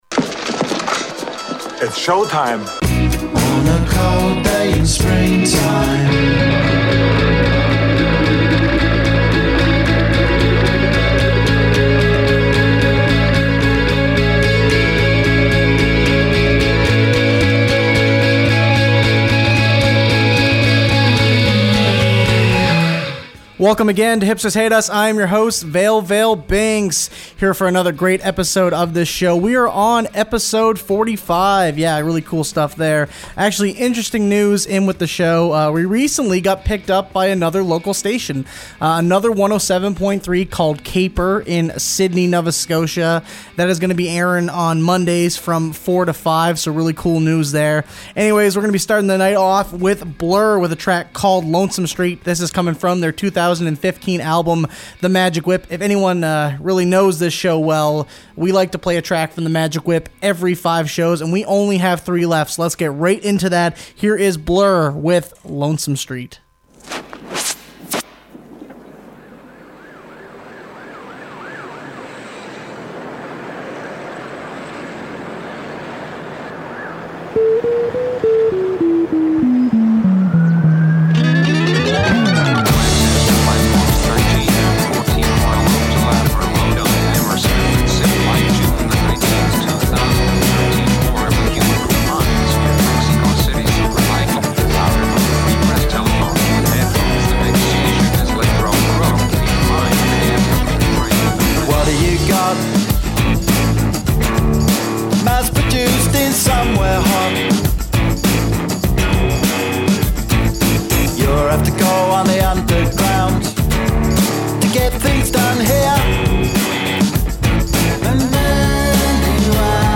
An Open Format Music Show. Britpop, Electronica, Hip-Hop, Alternative Rock, and Canadian music